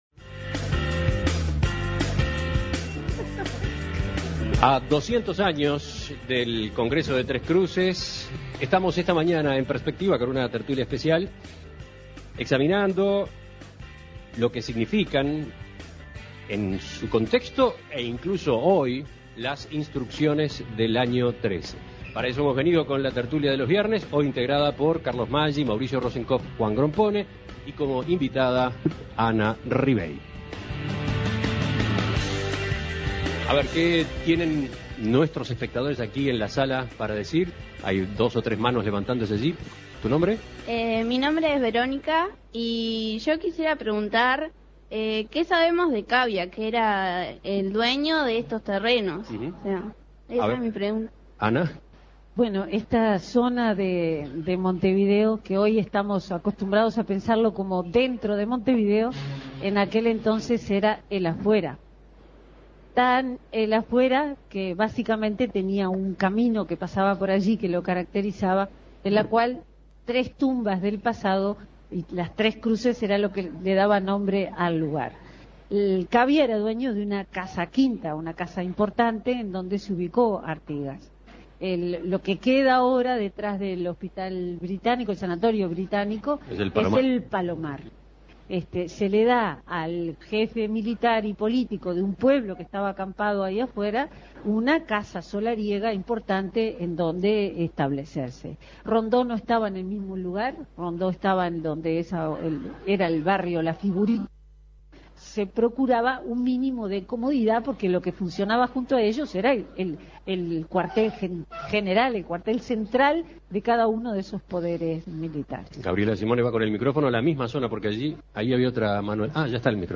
Segunda parte de La Tertulia especial desde el Liceo N° 8, conmemorando el bicentenario de las Instrucciones del año XIII